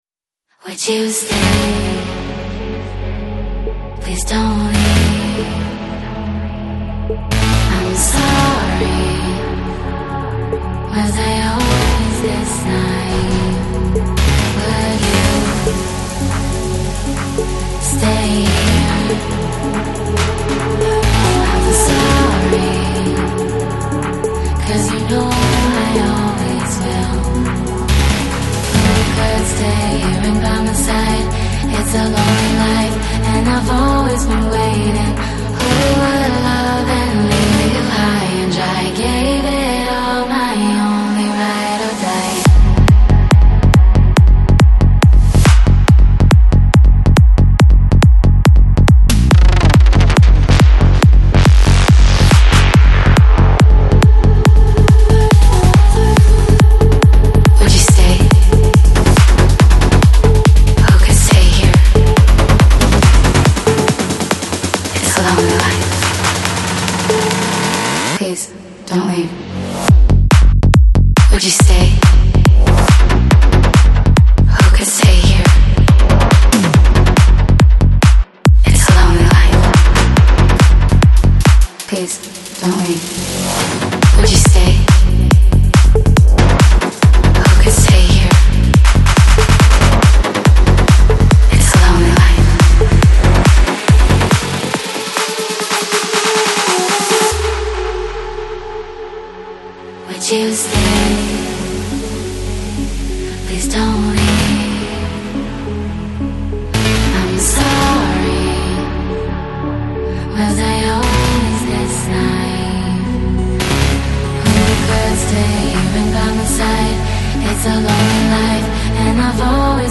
Жанр: Psy-Trance, Electro House